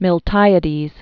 (mĭl-tīə-dēz) c. 550-489 BC.